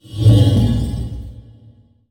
1069 Concrete Block Drag 02 2s 0.04 MB